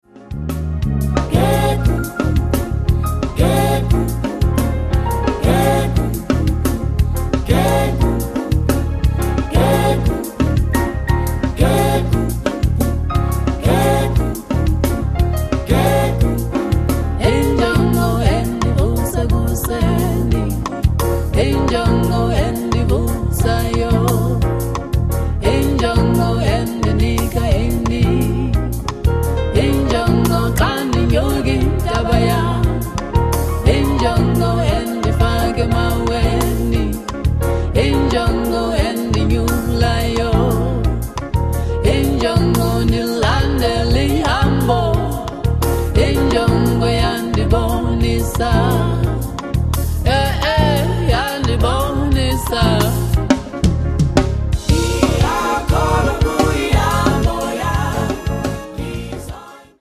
voice
chorus
guitar
keyboards & piano
bass
drums
percussions